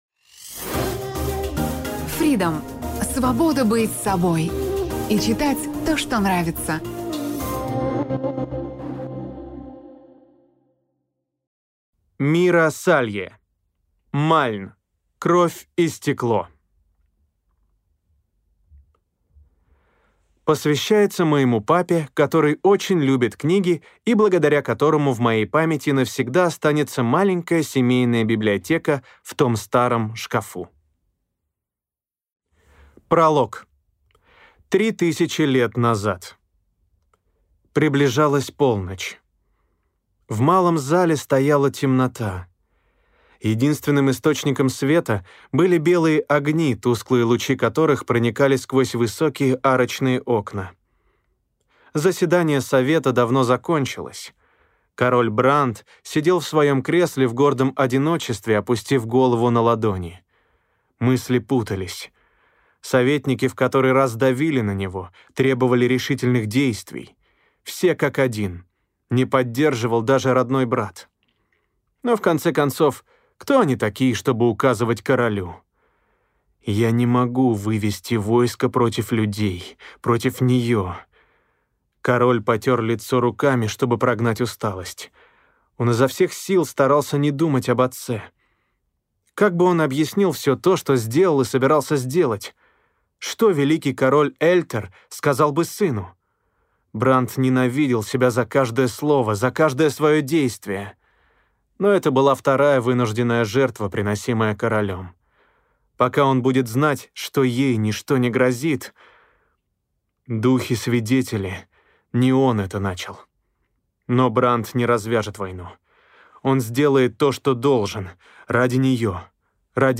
Аудиокнига Мальн. Кровь и стекло | Библиотека аудиокниг